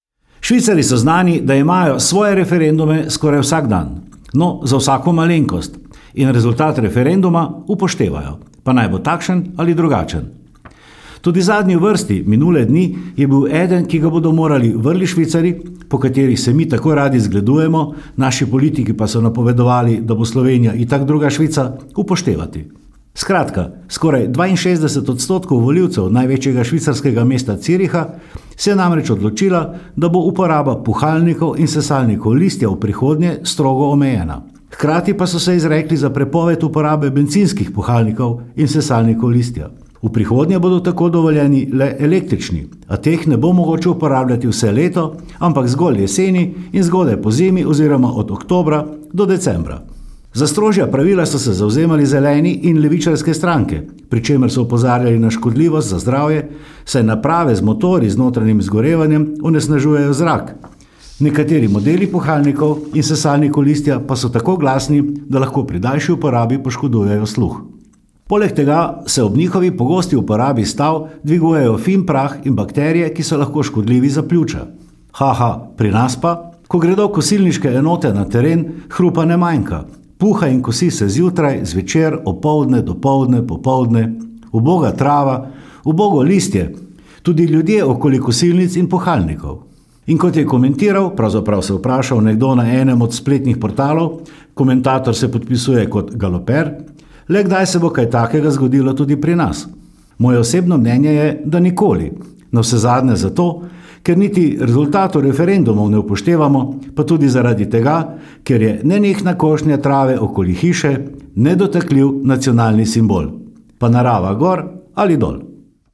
Komentar je stališče avtorja in ne nujno tudi uredništva.